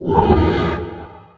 255081e1ee Divergent / mods / Soundscape Overhaul / gamedata / sounds / monsters / poltergeist / idle_0.ogg 25 KiB (Stored with Git LFS) Raw History
idle_0.ogg